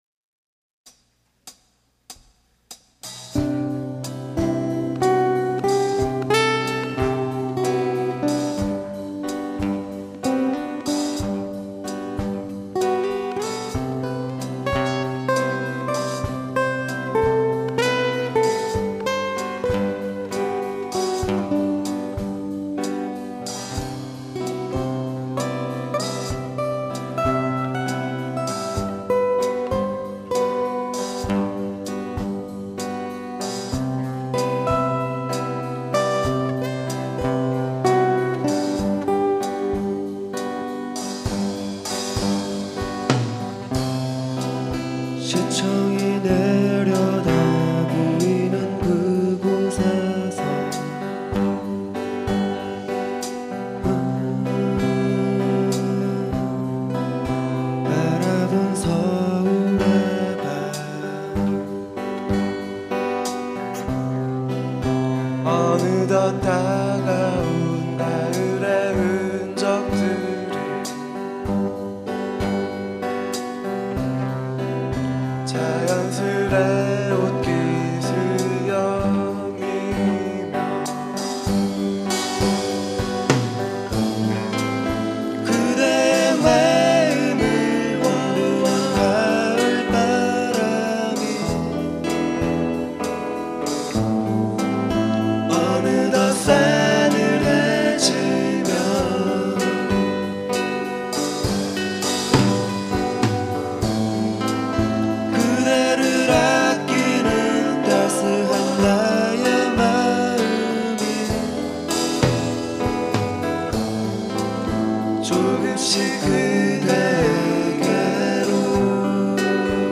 2004년 제 24회 정기 대공연
홍익대학교 신축강당
노래
어쿠스틱기타
베이스